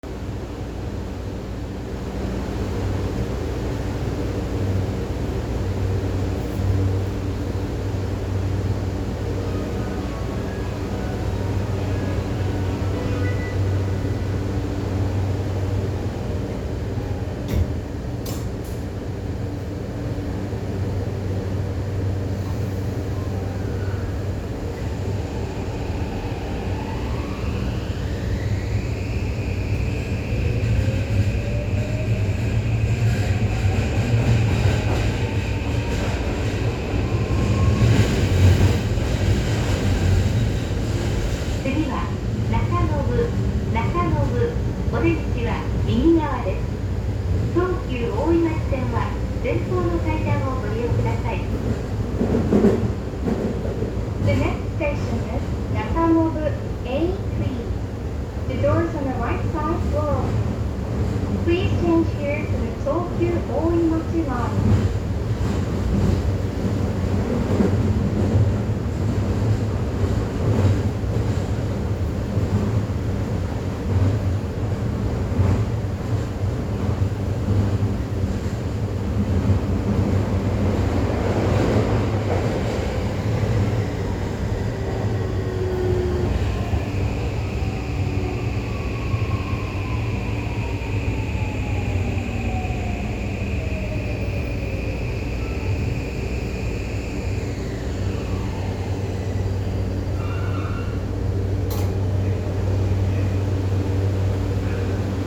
・5500形走行音
自動放送が搭載されているのは5300形と同様ですが、音質がとてもクリアなものとなった為聞こえやすくなりました。走行装置は三菱SiCのVVVF。なかなか特徴的な音で、近年の新型車としてはかなり個性が出ている部類なのではないでしょうか。